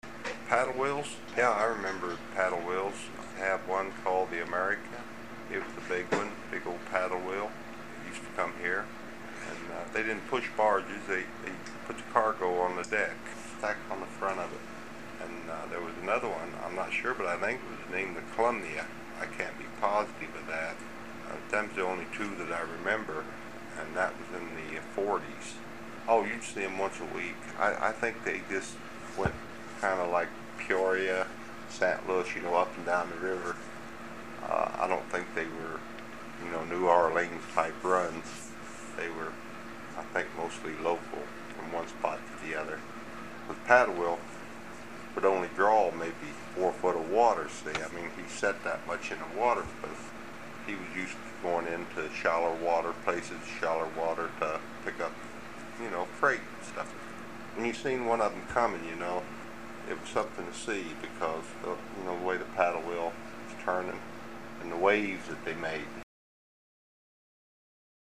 HTR Oral History, 08/18/1